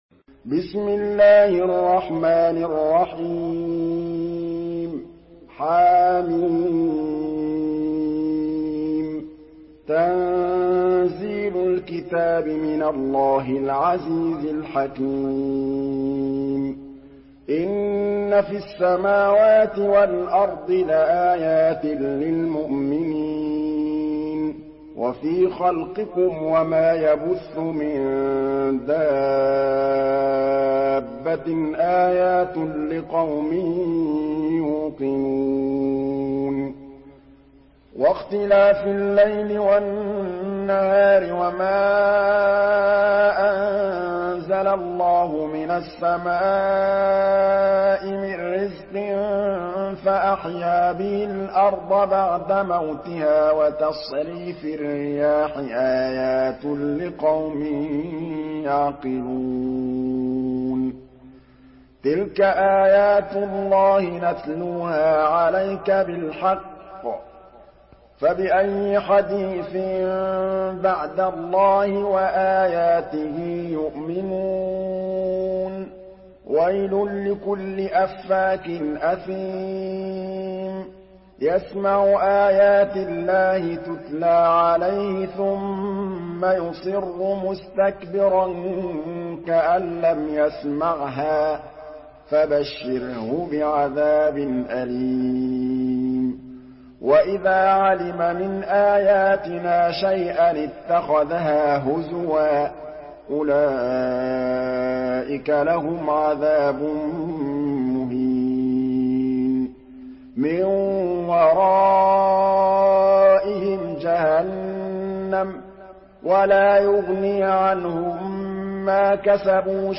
Surah আল-জাসিয়া MP3 by Muhammad Mahmood Al Tablawi in Hafs An Asim narration.
Murattal Hafs An Asim